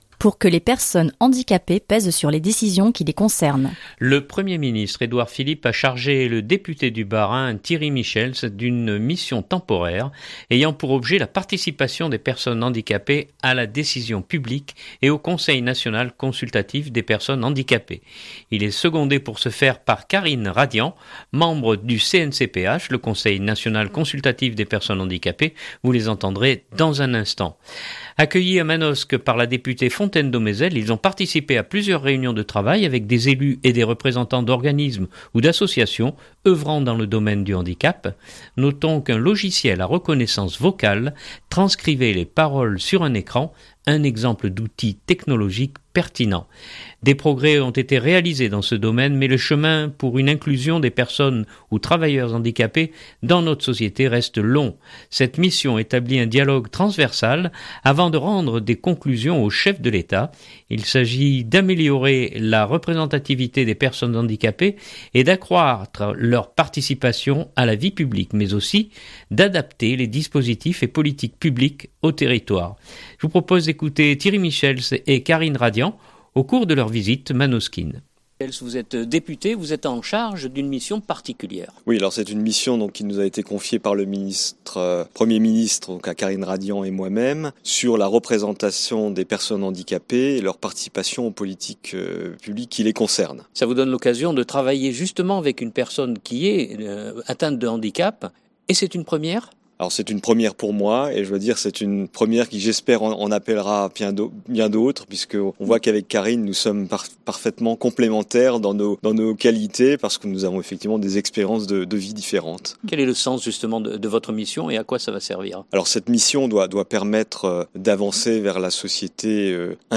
reportage-handicap-2019-03-18.mp3 (2.83 Mo)